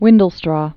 (wĭndl-strô)